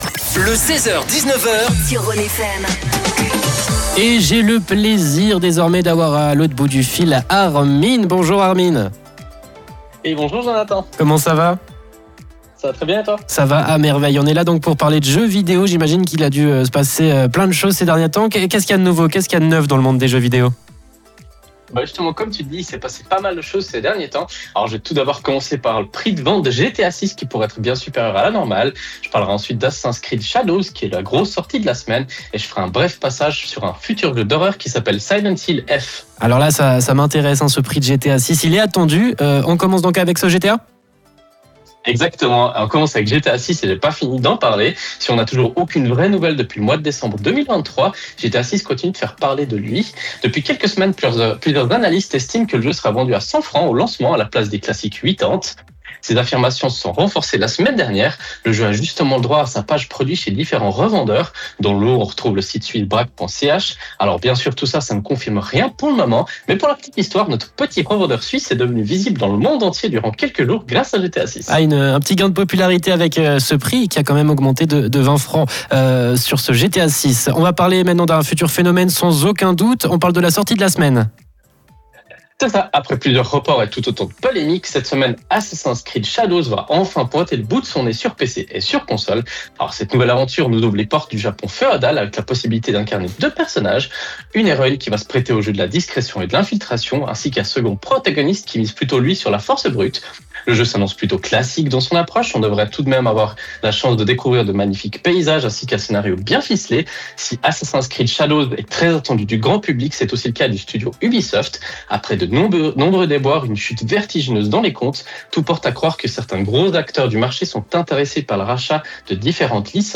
Comme tous les lundis, nous avons la chance de présenter notre chronique gaming sur la radio Rhône FM.
Vous pouvez réécouter le direct Rhône FM via le flux qui se trouve juste en haut de l’article.